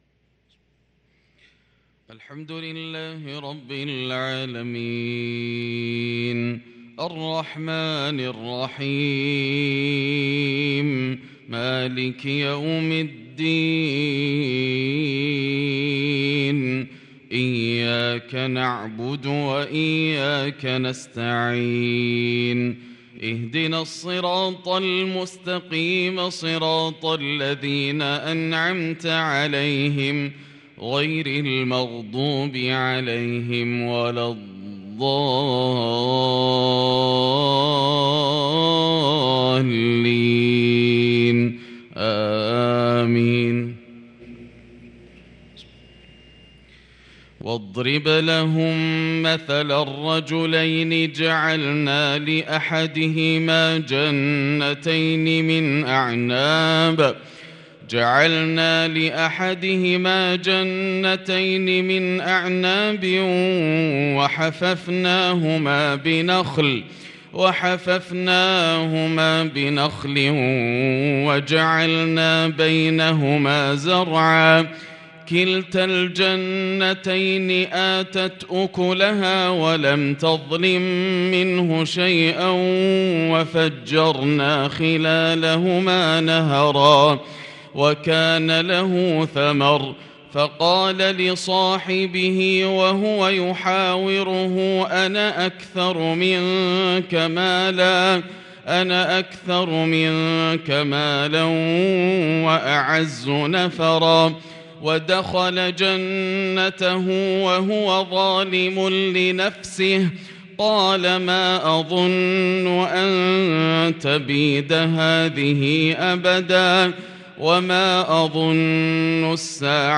صلاة الفجر للقارئ ياسر الدوسري 21 شوال 1443 هـ
تِلَاوَات الْحَرَمَيْن .